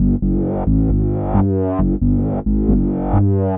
bass.wav